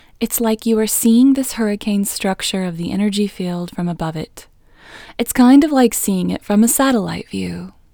IN – the Second Way – English Female 9